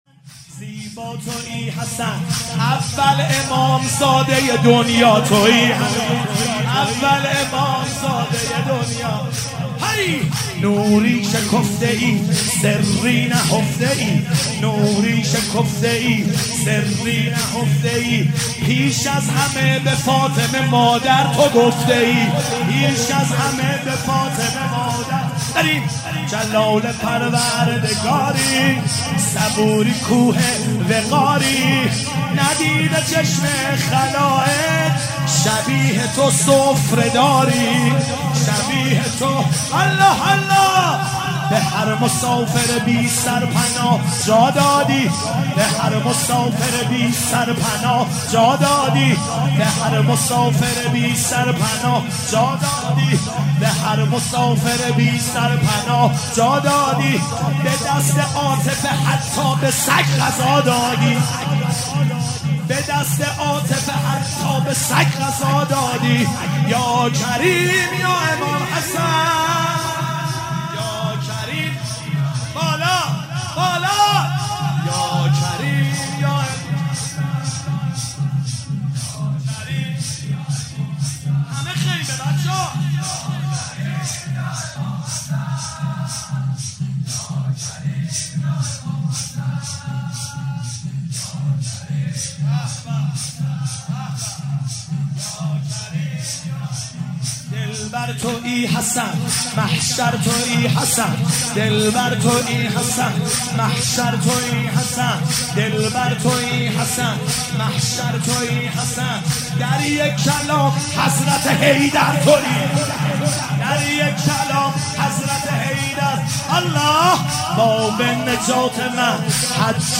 شب ششم محرم 97 - زمینه - زیبا تویی حسن اول امام زاده دنیا